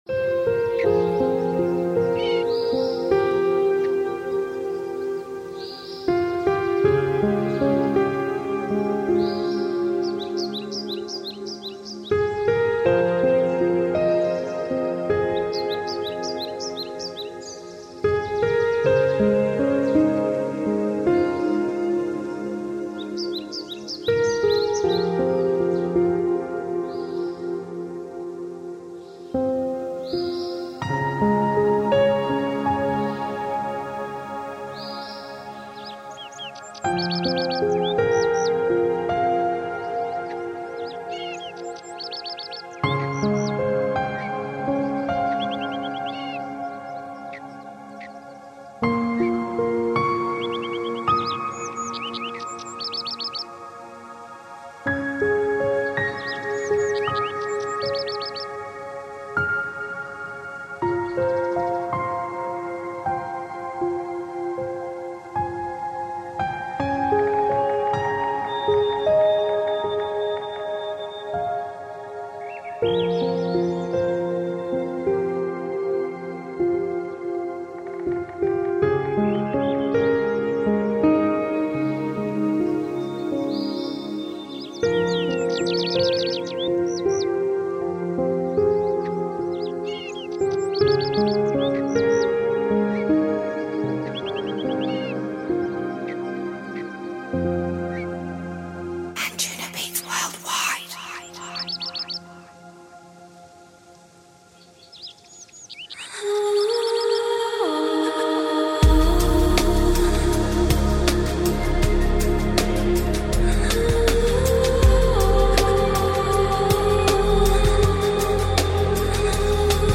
RELAKSMUZYKASPENIEMPTIC_muzlishko.ru_.mp3